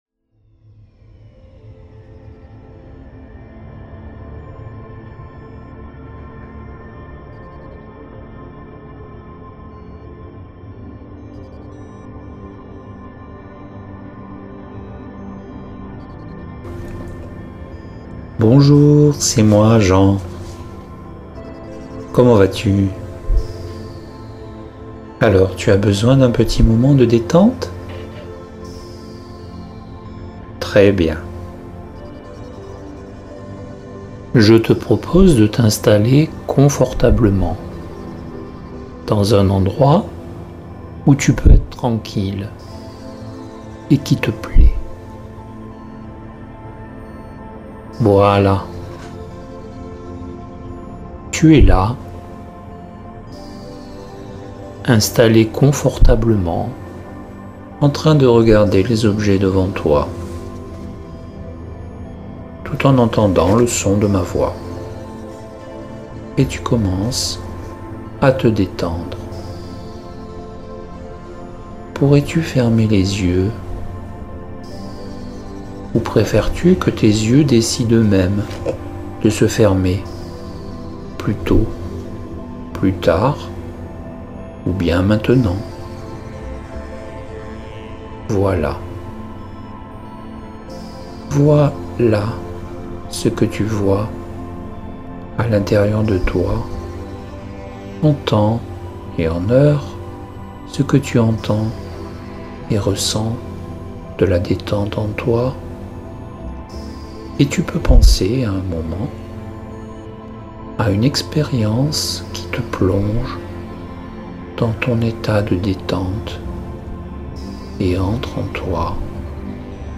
Les audios “Transes Positives” sont de courtes séances d’hypnose dont l’objectif est d’apporter du bien-être, du positif, des sensations agréables, du soulagement ou de l’amélioration d’états internes négatifs.
transe-detente-20mn.mp3